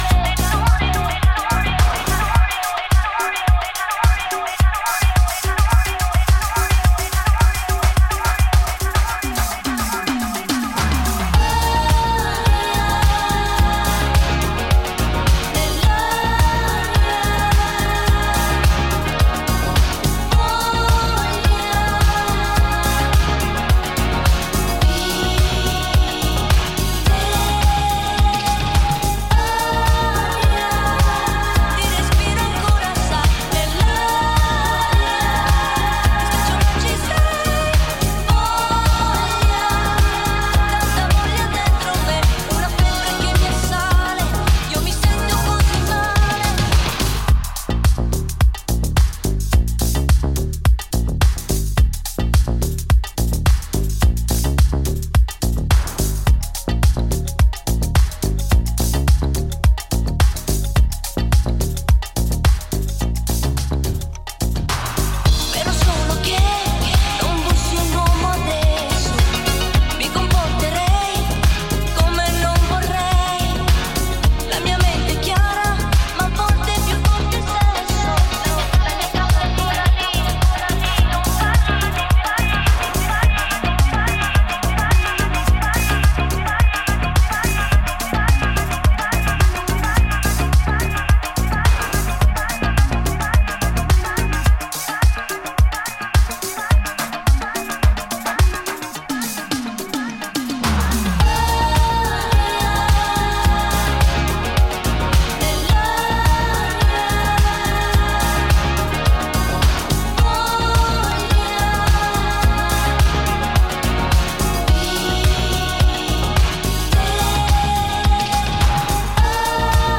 > NU DISCO・BALEARIC・NU JAZZ・CROSSOVER・REGGAE
ジャンル(スタイル) DISCO / BALEARIC